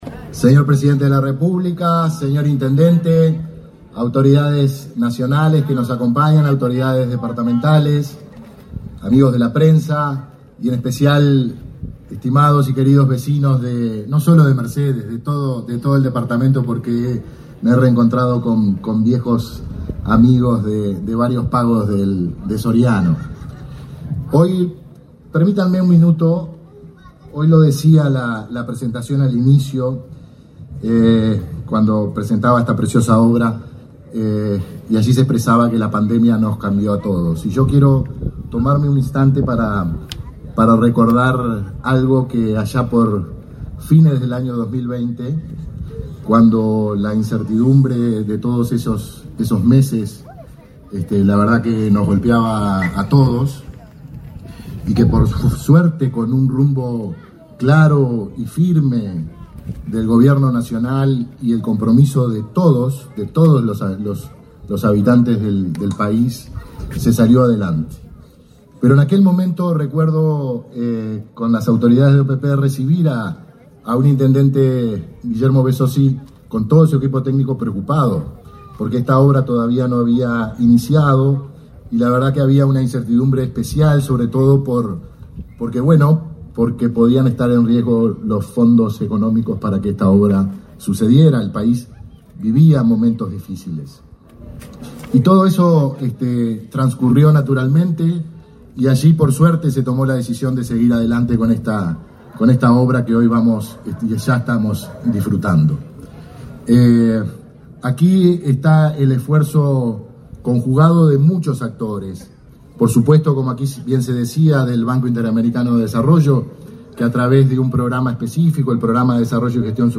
Acto de inauguración del centro deportivo en Mercedes
Acto de inauguración del centro deportivo en Mercedes 20/11/2024 Compartir Facebook X Copiar enlace WhatsApp LinkedIn Este 20 de noviembre fue inaugurado el centro deportivo en Mercedes, con la presencia del presidente de la República, Luis Lacalle Pou. En el evento, el coordinador general de la Oficina de Planeamiento y Presupuesto, Guillermo Bordoli, y el intendente de Soriano, Guillermo Besozzi, realizaron declaraciones.